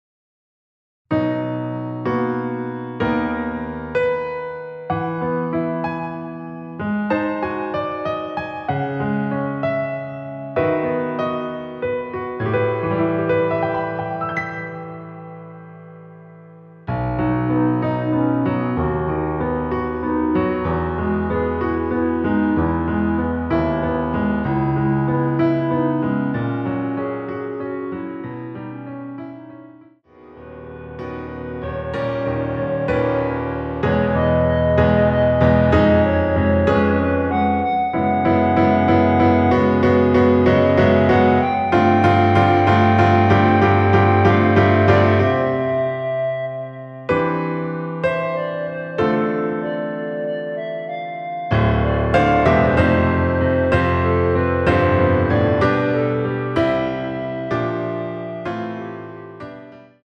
반주가 피아노 하나만으로 되어 있습니다.(아래의 유튜브 동영상 참조)
원키 멜로디 포함된 피아노 버전 MR입니다.
앞부분30초, 뒷부분30초씩 편집해서 올려 드리고 있습니다.
중간에 음이 끈어지고 다시 나오는 이유는